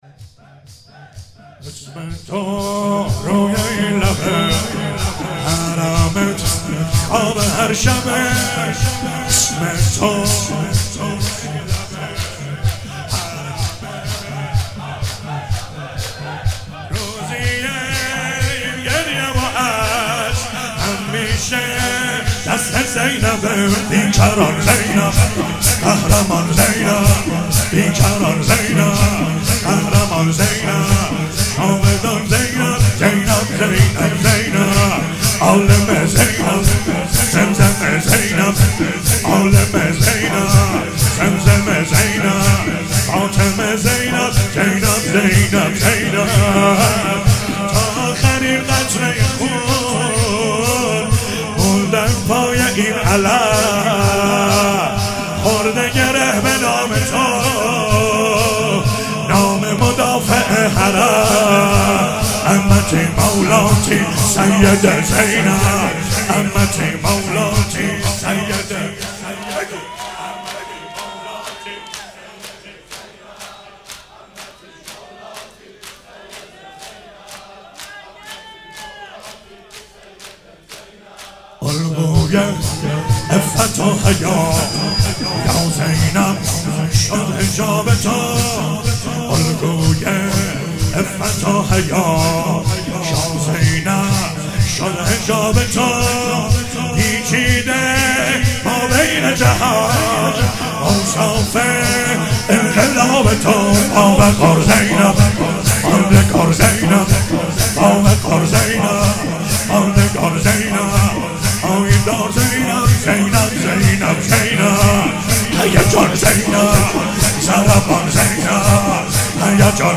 هفتگی 29 آذر 97 - شور - اسم تو روی این لبه